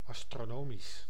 Ääntäminen
IPA : /ˌæs.trəˈnɒm.ɪk.əl/